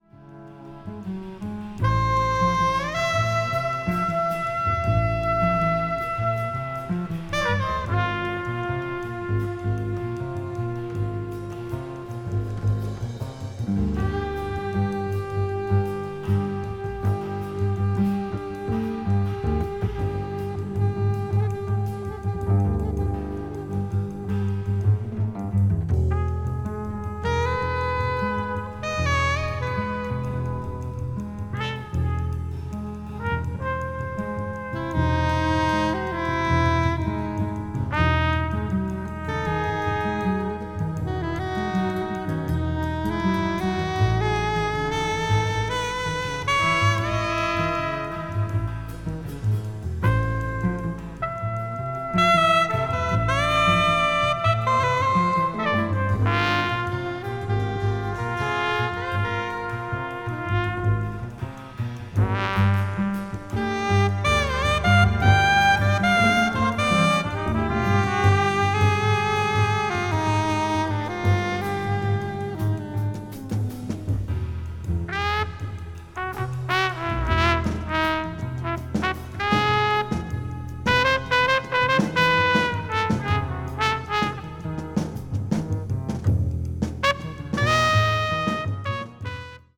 非常にスピリチュアルなサウンド
avant-jazz   contemporary jazz   spritual jazz